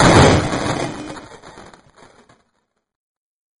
ANGRY_TEXT_BOX.mp3